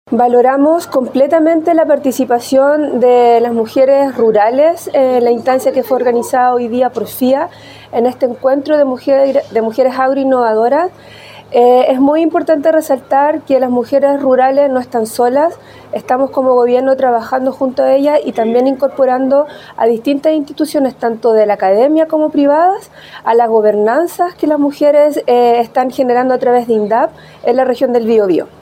Mientras que Fabiola Lara, directora regional INDAP, apuntó que “es muy importante resaltar que las mujeres rurales no están solas, estamos trabajando junto a ellas y también incorporando a distintas instituciones”.